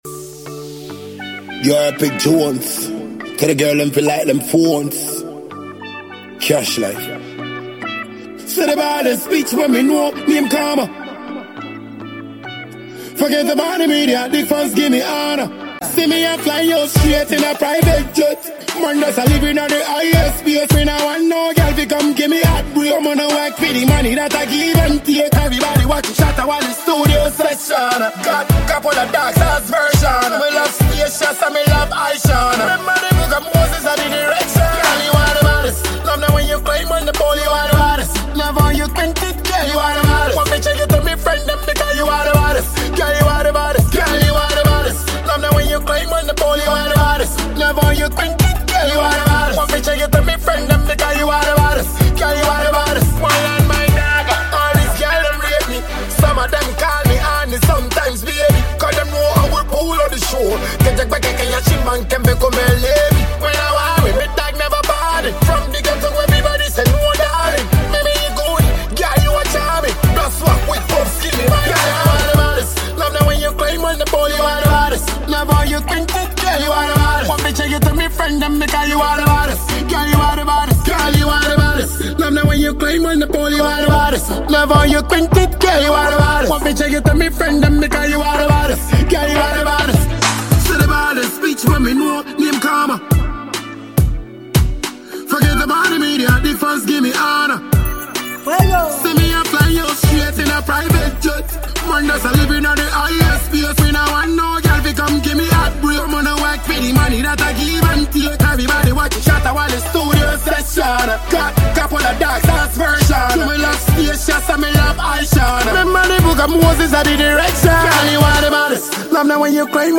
dance-hall
The new dance-hall song